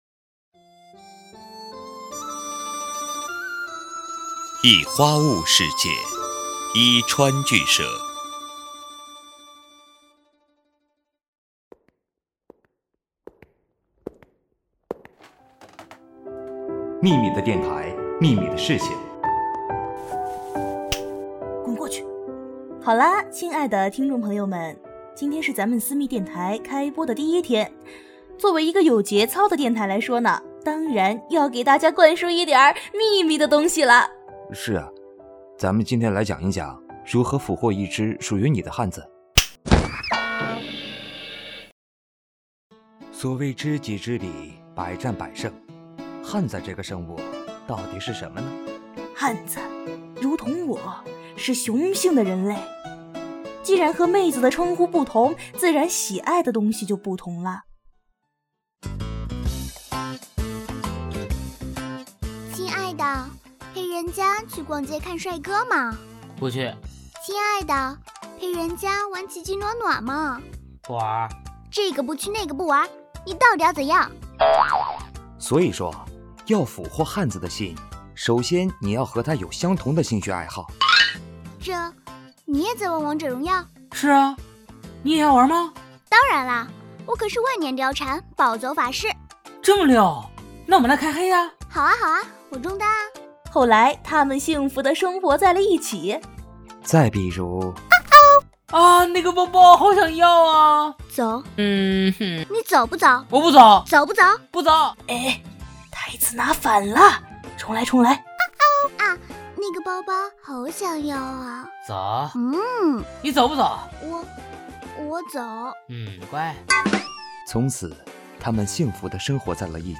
广播剧
喜剧
【壹川剧社出品】全年龄搞笑广播剧《秘密电台》第一期
本作品中所采用的配乐、音效等素材均来自于互联网，著权归原作者所有。